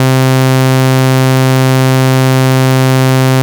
saw.wav